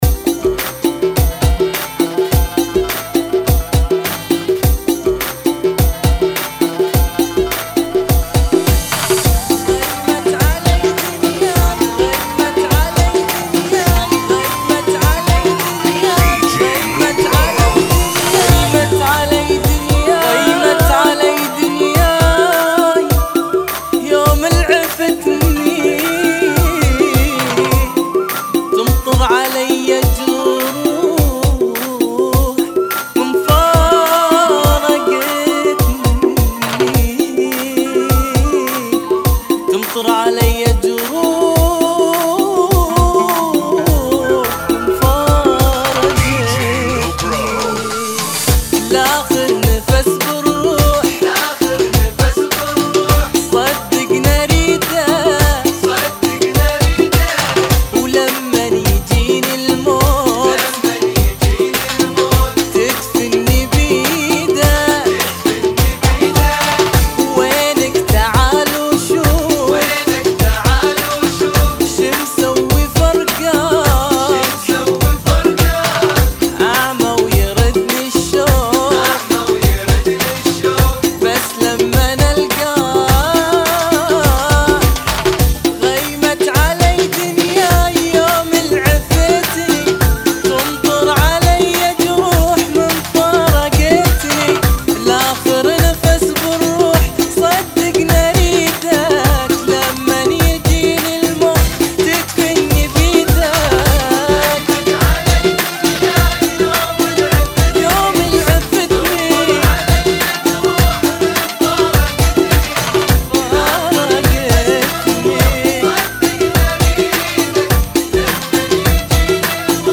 [ 104 BPM ]